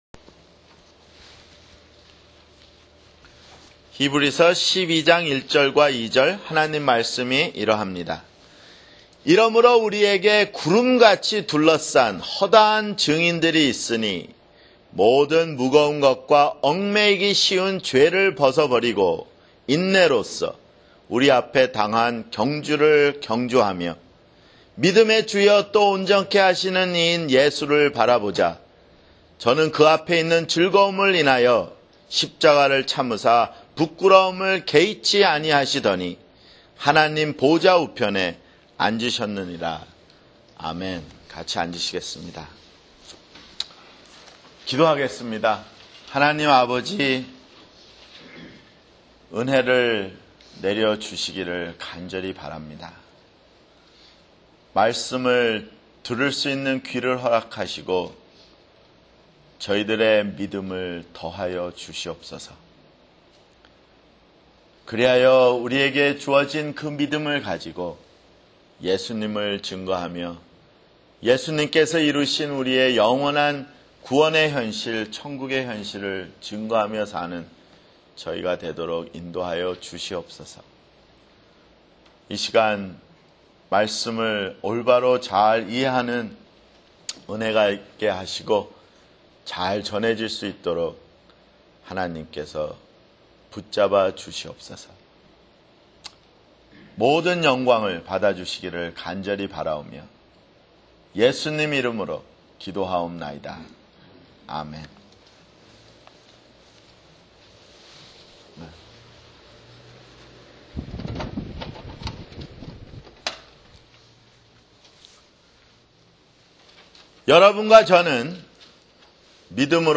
[주일설교] 히브리서 12:1-2